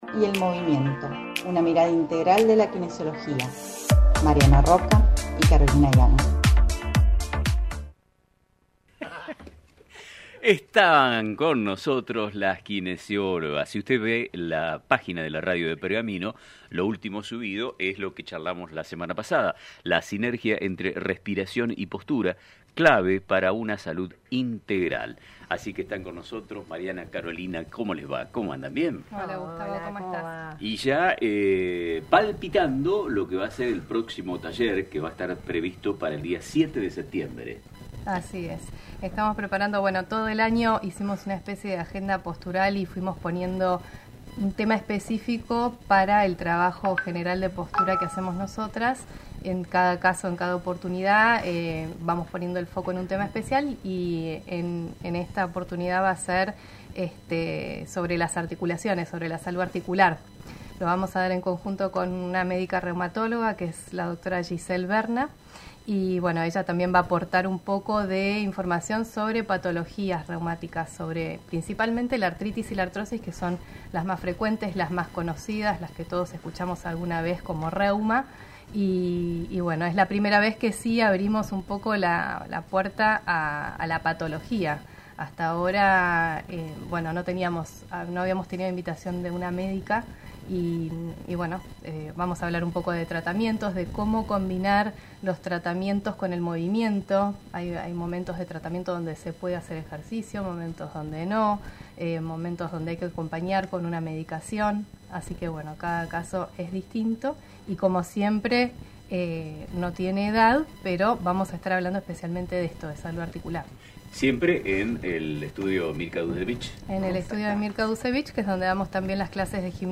Durante la entrevista, se destacó la relación entre las articulaciones y la postura, subrayando que ambas son fundamentales para una vida saludable y sin dolores.